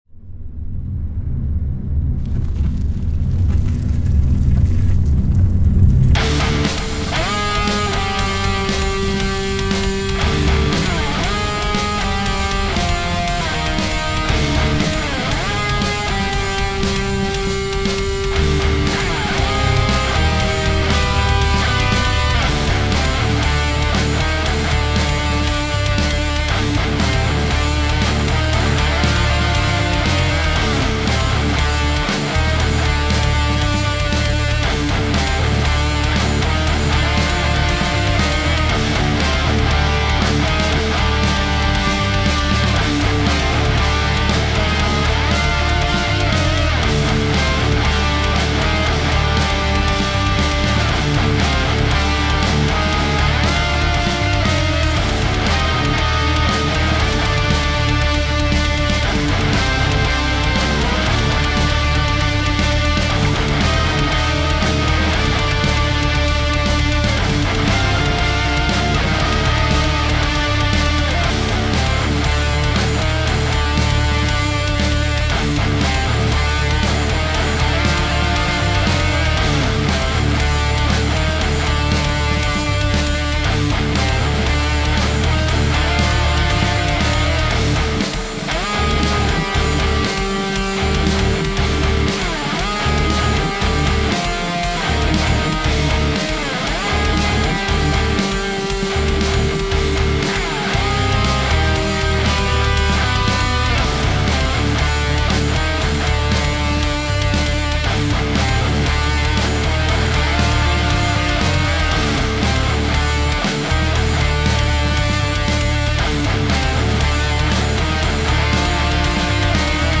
響くような低音とモジュレーションの効いたヘビーなギターが緊張感を出している。
タグ: Metal かっこいい 冒険 戦闘曲 激しい/怒り コメント: 巨大な生物との遭遇シーンをイメージした楽曲。